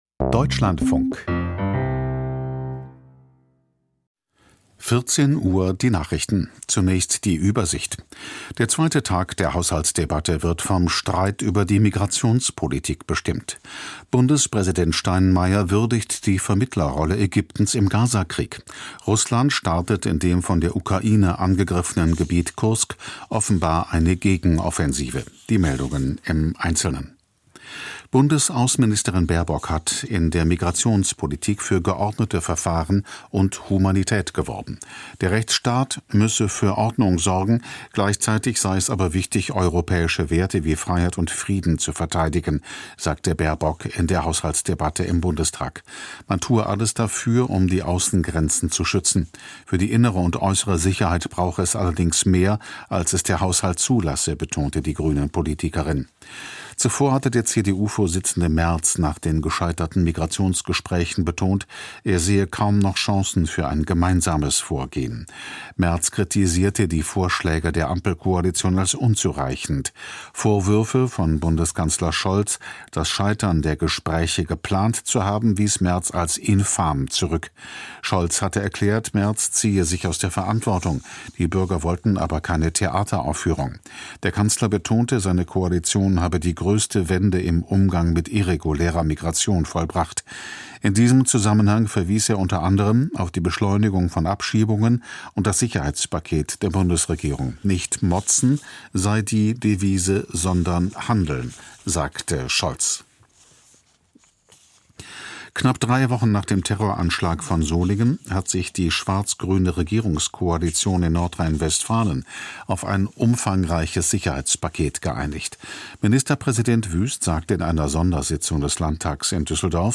Die Nachrichten